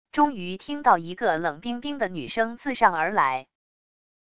终于听到一个冷冰冰的女声自上而来――wav音频